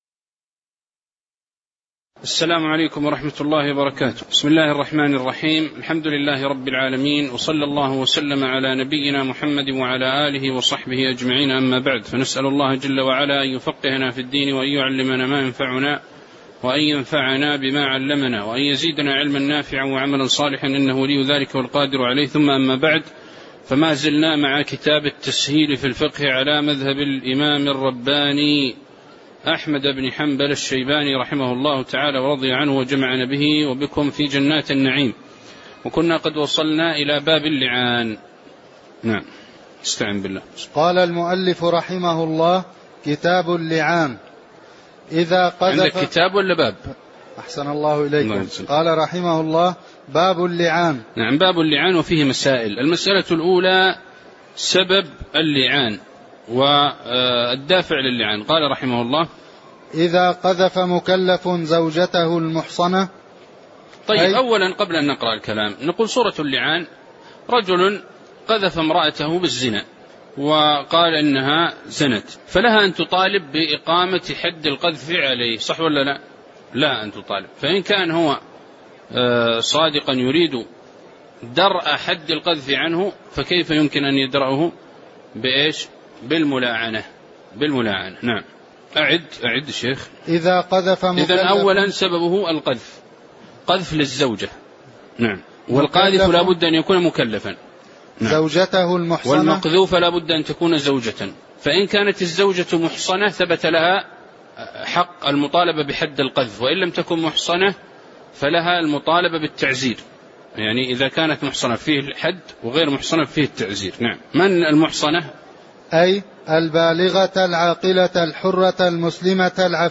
تاريخ النشر ٢١ شوال ١٤٣٩ هـ المكان: المسجد النبوي الشيخ